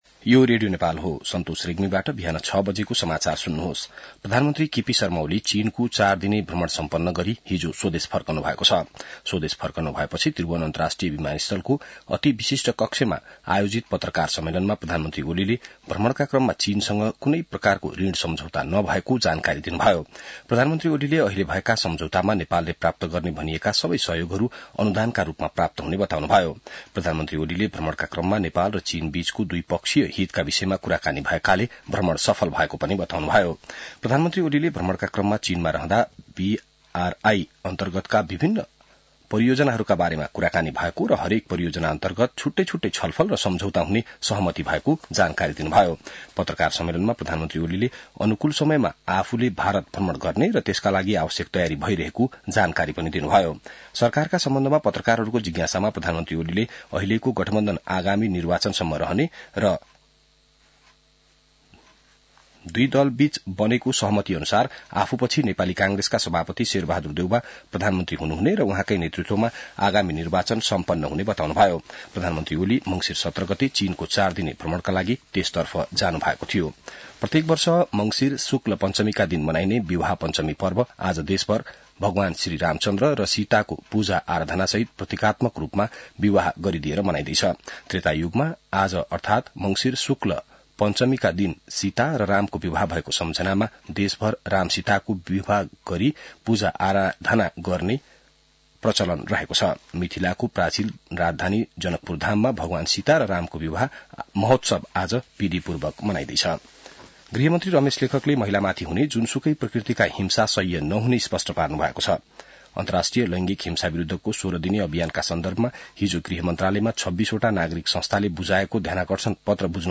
बिहान ६ बजेको नेपाली समाचार : २२ मंसिर , २०८१